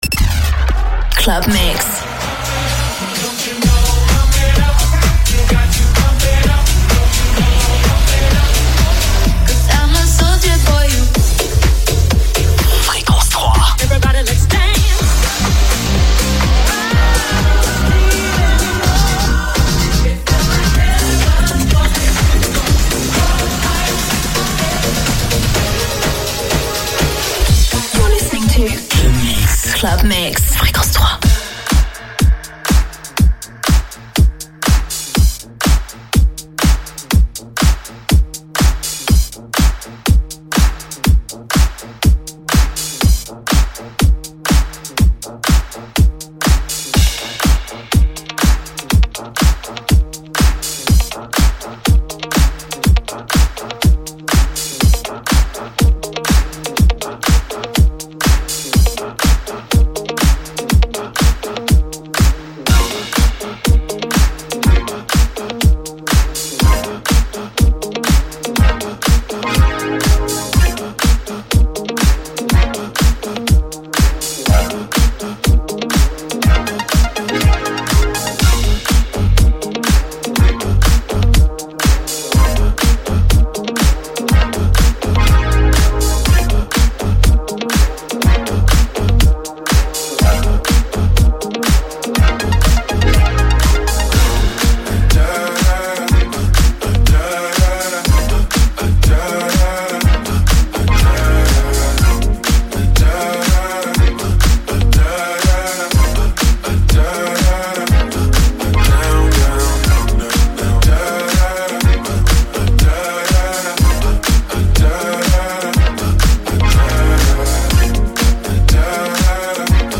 avec les DJ’s Résidents !
Ré-écoutez le set du samedi 18 mars: